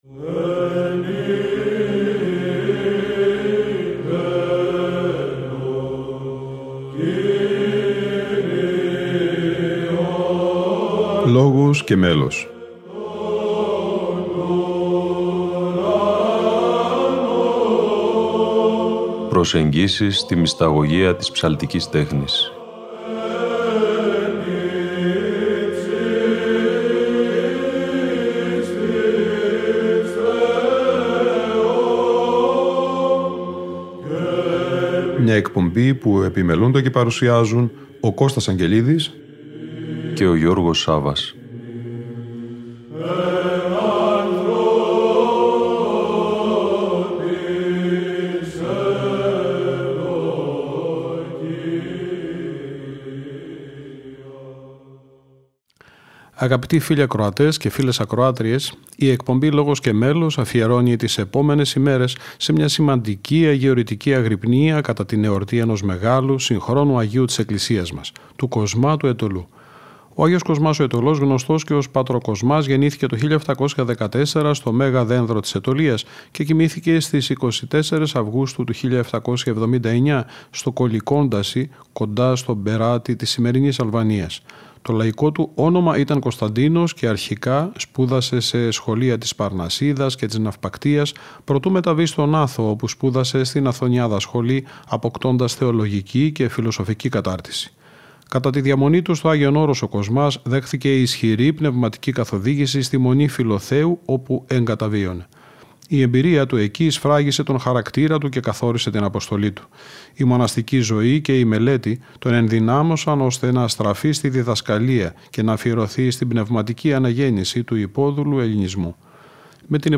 Αγρυπνία Αγ. Κοσμά Αιτωλού - Ι. Μ. Φιλοθέου 2009 (Α΄)